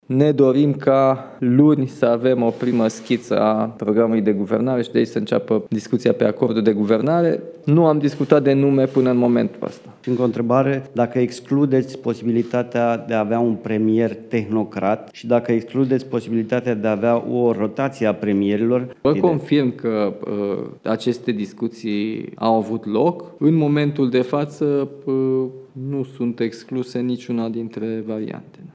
„Luni, vom avea o primă schiță a programului de guvernare”, spune președintele Nicușor Dan, în prima sa conferință de presă organizată la Cotroceni.